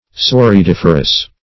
Search Result for " sorediferous" : The Collaborative International Dictionary of English v.0.48: Sorediferous \Sor`e*dif"er*ous\, or Sorediiferous \So*re`di*if"er*ous\, a. [Soredium + -ferous.]